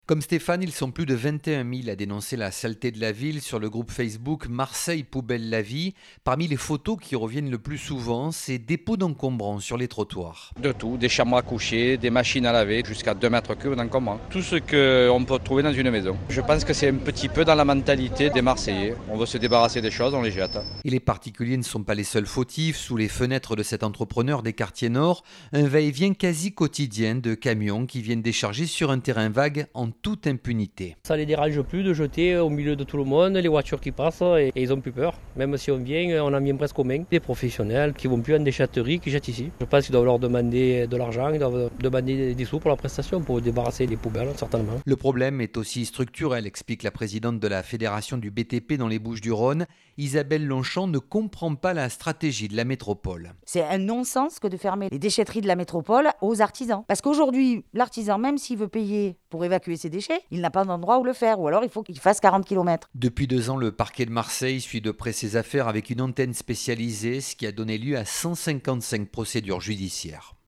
Reportage Sud Radio